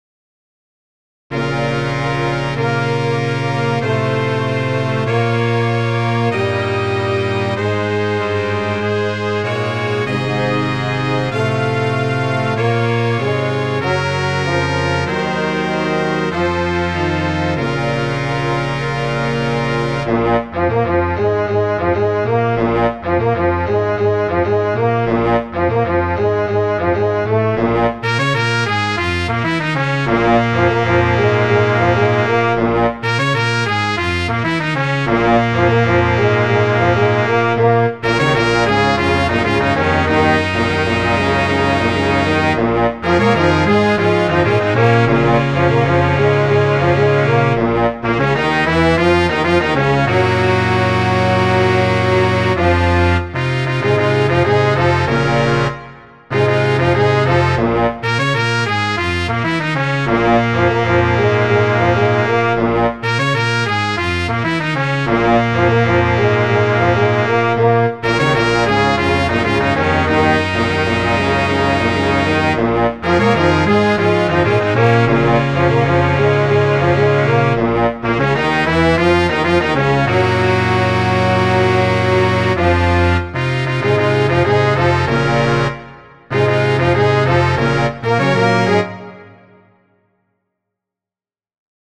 key Bb minor